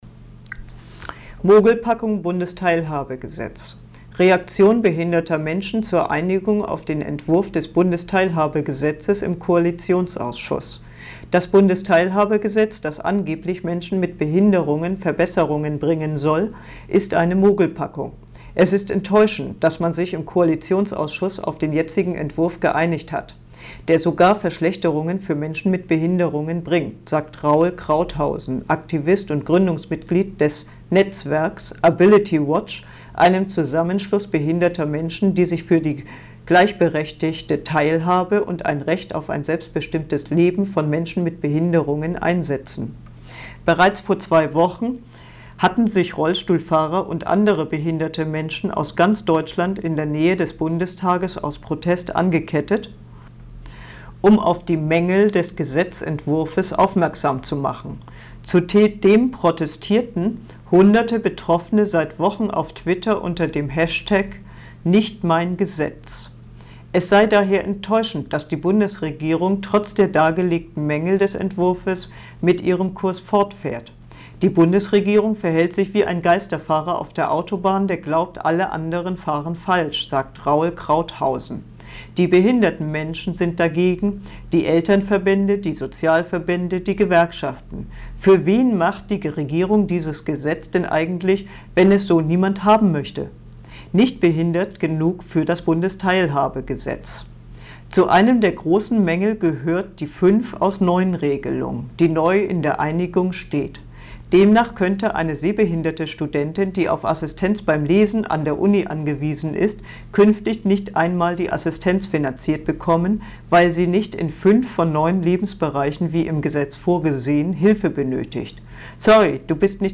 Beitrag vorgelesen